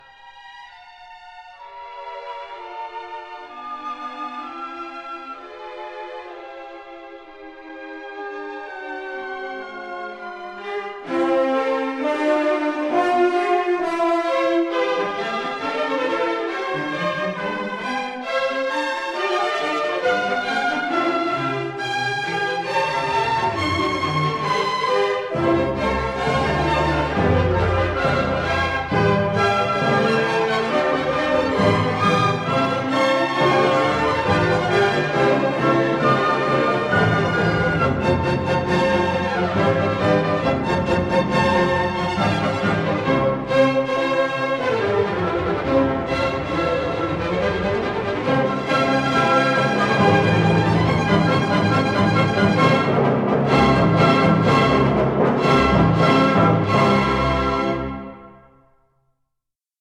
This is the Molto allegro in a performance by the